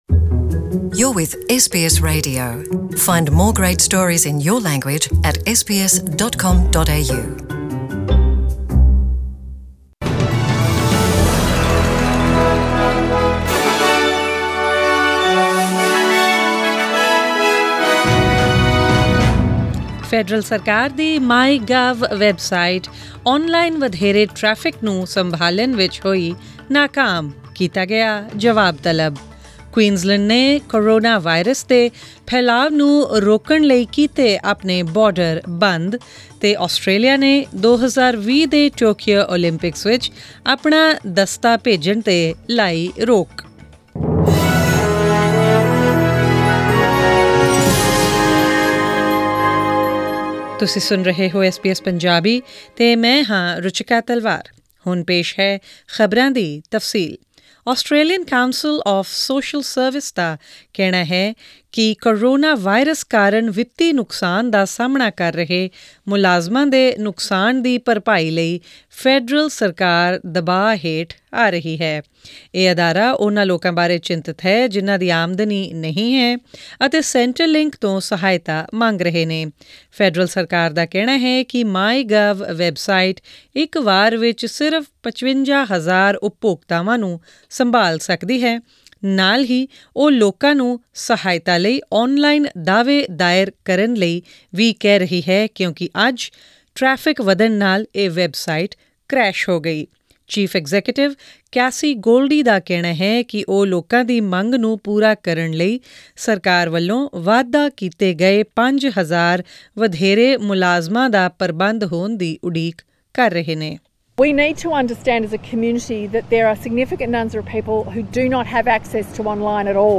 Australian News in Punjabi: 23 March 2020